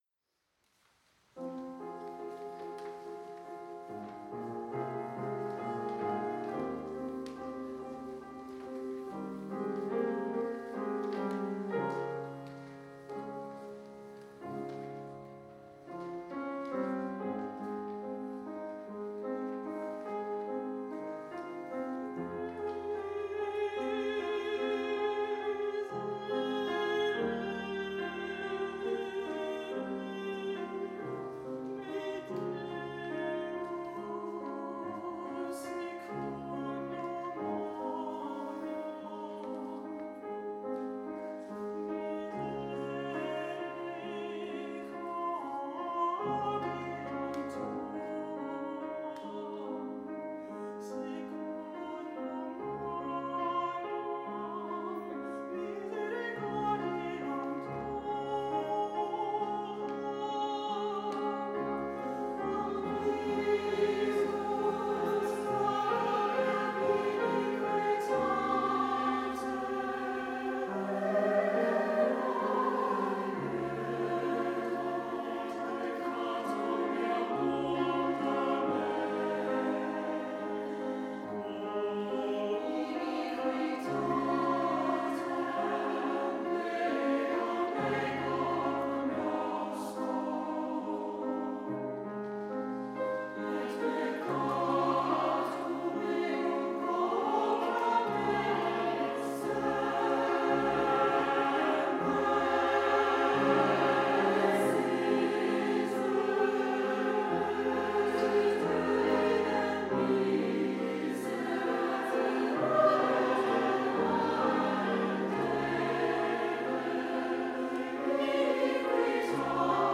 SATB with piano, Alto solo